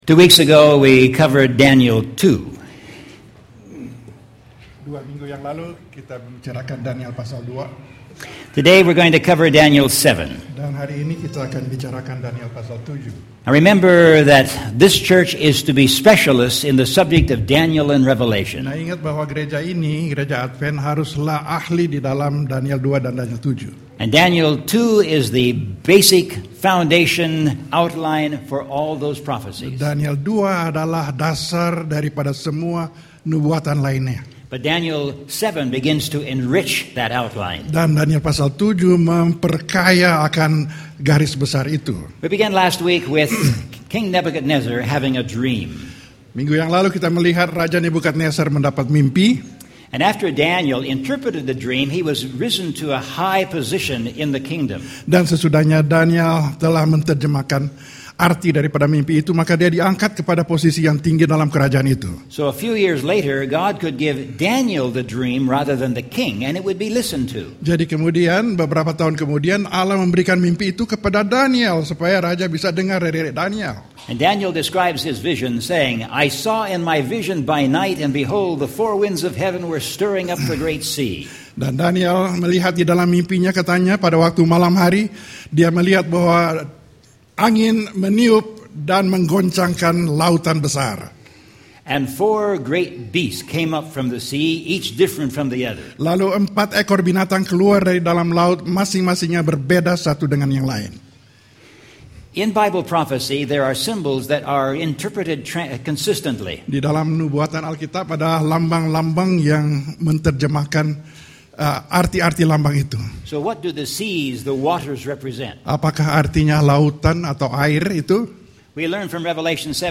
Seventh-day Adventist Church in Bloomington, CA